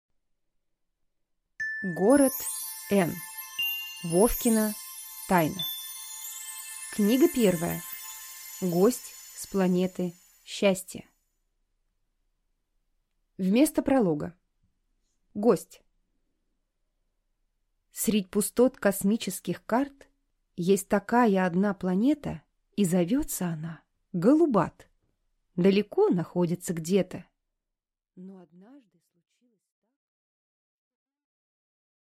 Аудиокнига Вовкина тайна | Библиотека аудиокниг